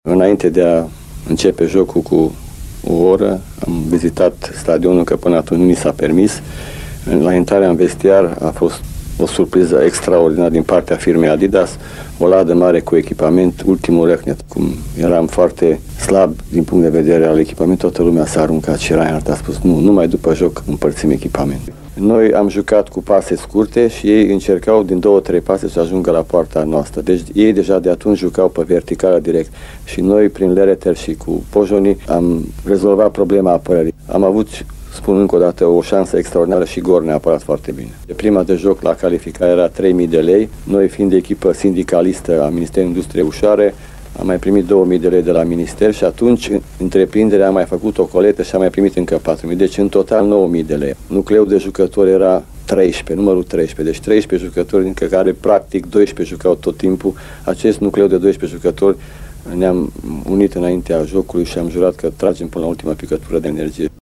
Pe lângă declarațiile perioadei actuale, dinaintea jocului cu Sepsi, de mâine seară ora 20, vă propunem ”voci” ale Bătrânei Doamne, din fonoteca Radio Timișoara.